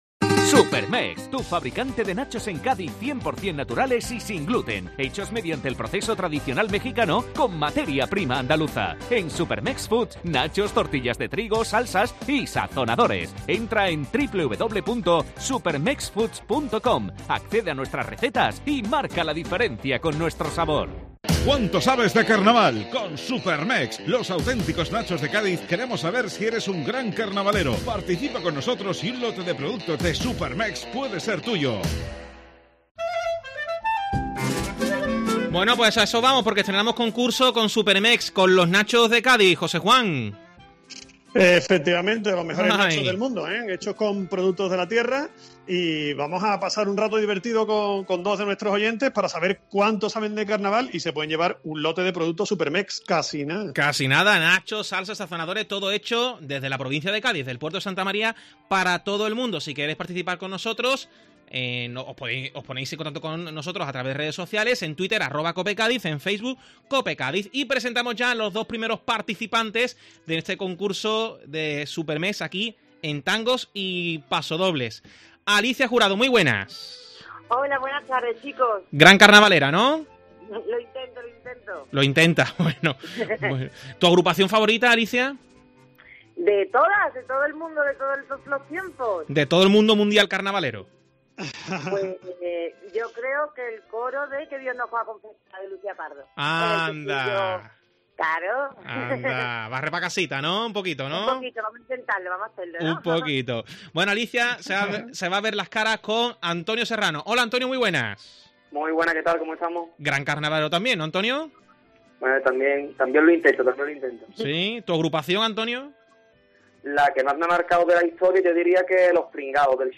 Todo ello en un divertido concurso donde dos participantes ponen a prueba sus conocimientos sobre el Carnaval. Coplas, autores, agrupaciones y sobre todo mucha diversión.